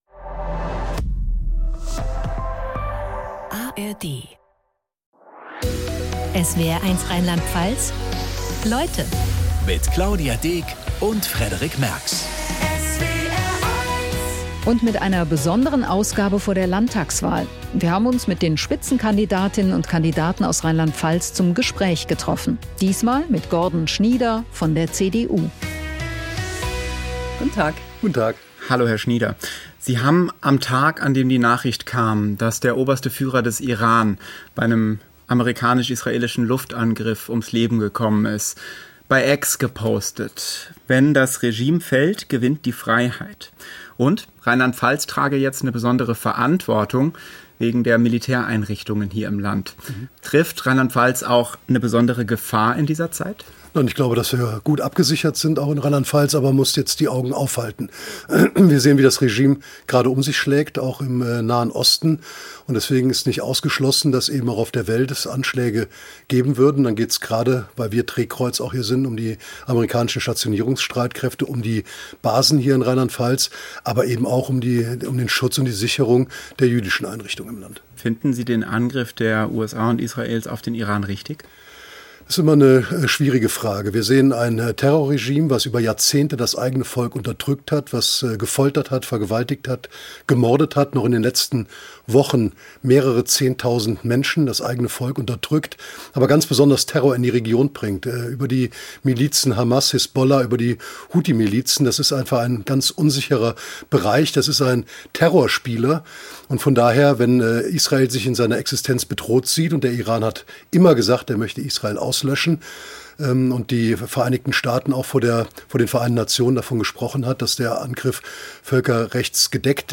Schafft es Schnieder trotzdem? Anlässlich der Landtagswahl 2026 in Rheinland-Pfalz sendet der SWR multimediale Interviews mit den Spitzenkandidatinnen und -kandidaten von SPD, CDU, Grünen, AfD, Freien Wählern, Linken und FDP.
In dieser Folge zu Gast: Gordon Schnieder von der CDU.